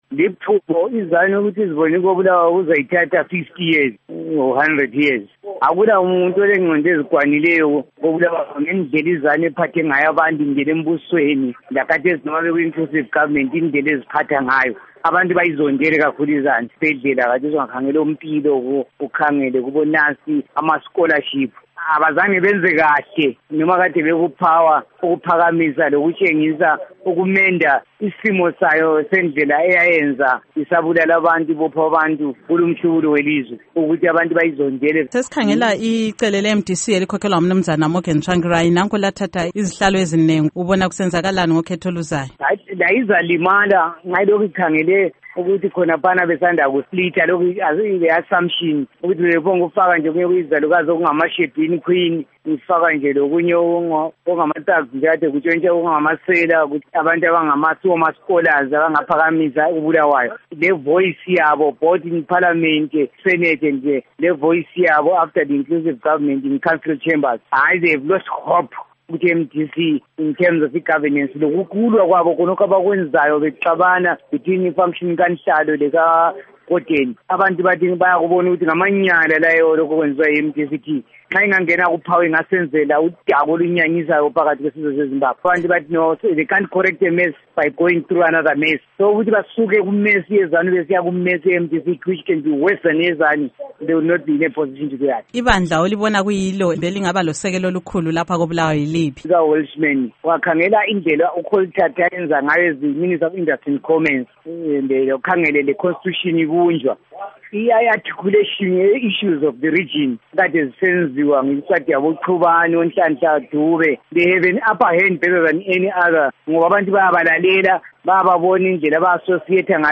Ingxoxo LoAlderman Charles Mpofu